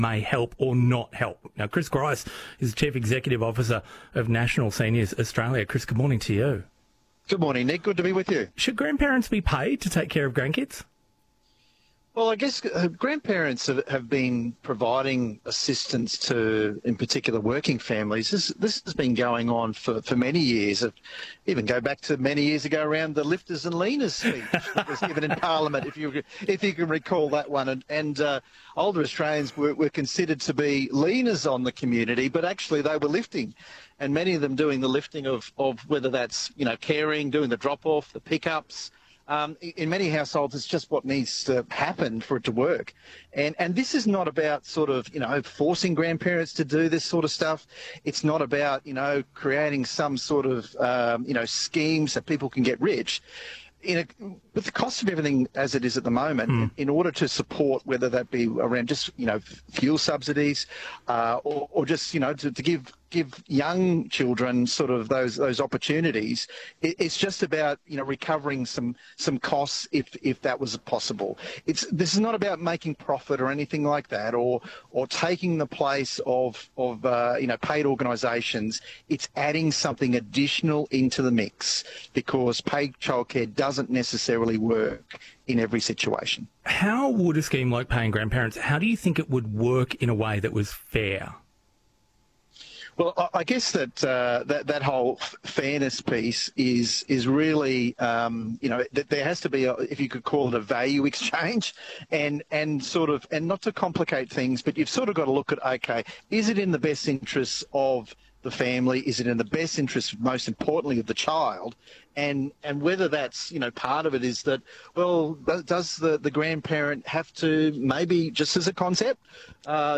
Nationals-Grandparents-Policy-ABC-Interview.mp3